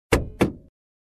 Звуки багажника
Звук открывания багажника при нажатии на ручку